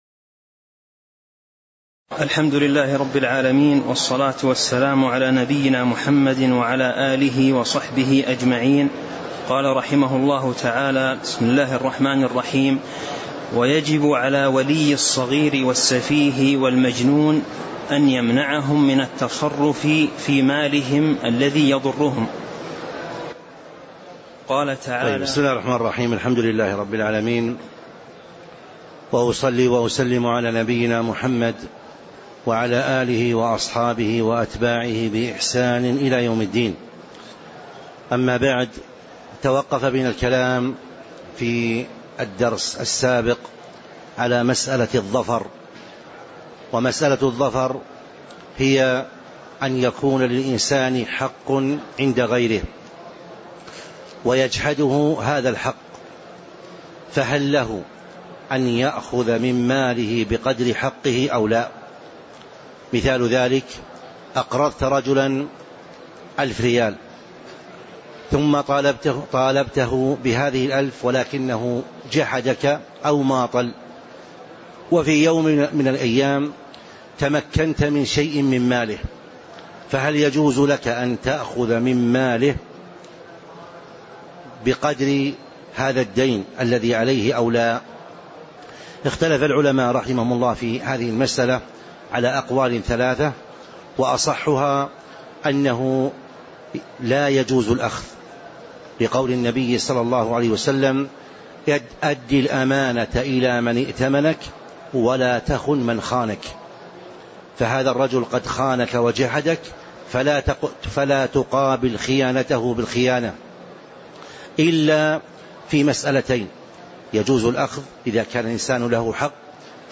تاريخ النشر ٢٢ محرم ١٤٤٦ هـ المكان: المسجد النبوي الشيخ